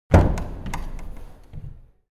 Single Footstep On Old Wood Floor Sound Effect
A single, heavy footstep echoing on an old, creaky wooden floor. Heavy tread on old planks sound. Perfect for horror scenes, suspenseful moments, or historical settings.
Single-footstep-on-old-wood-floor-sound-effect.mp3